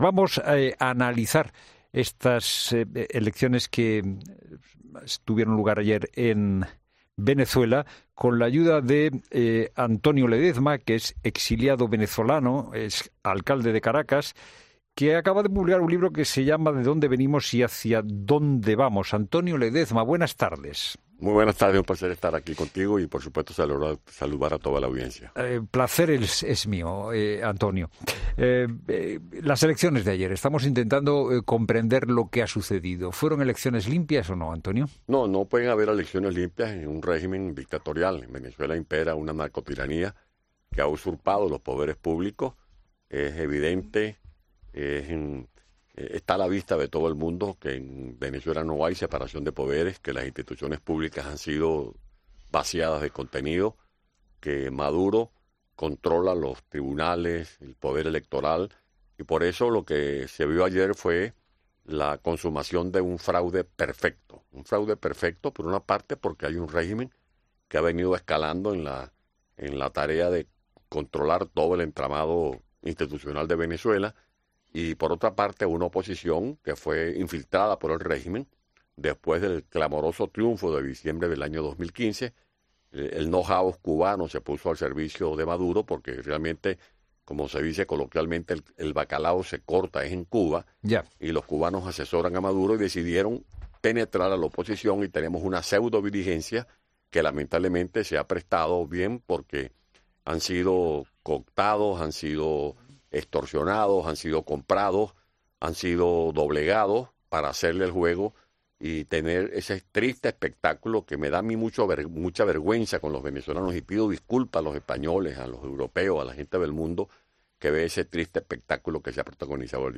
tres expertos venezolanos que han criticado las irregularidades de las elecciones del país